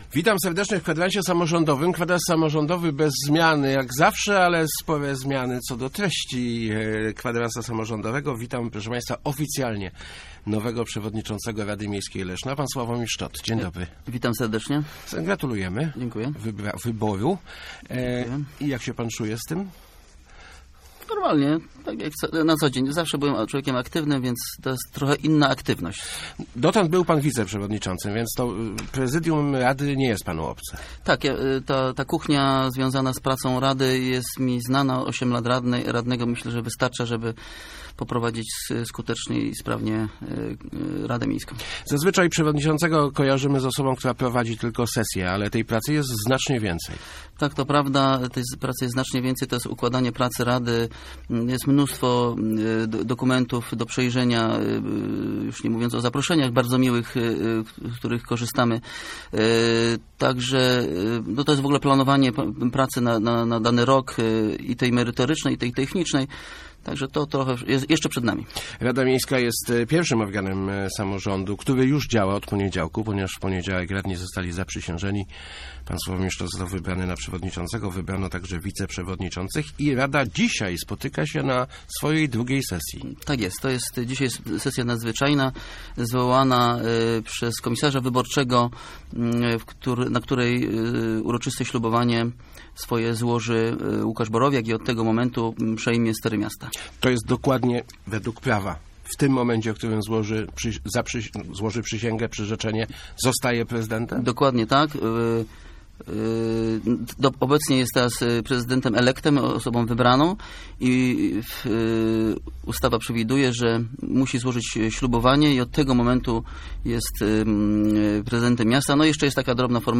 Gościem Kwadransa Samorządowego był Sławomir Szczot, Przewodniczący Rady Miejskiej Leszna.